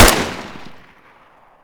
ump45_shoot4.ogg